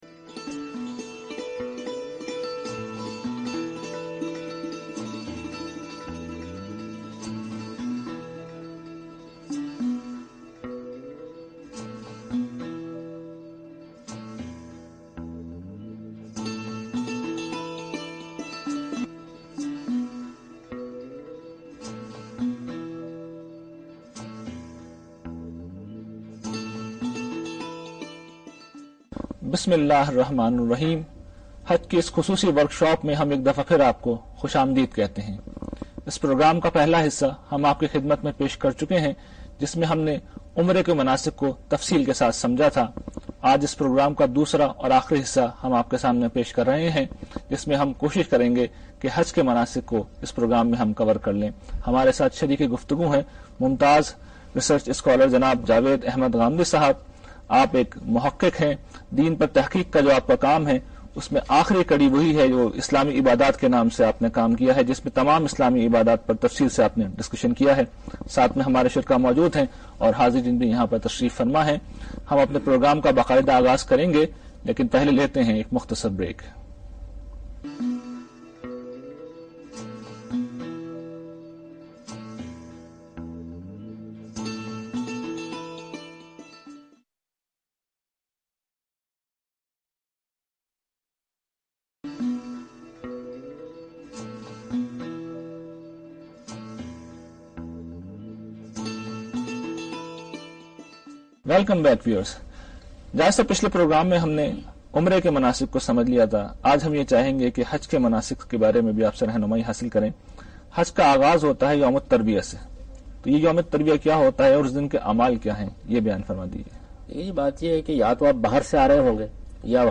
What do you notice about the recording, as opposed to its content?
The program was aired on Aaj TV (in Pakistan).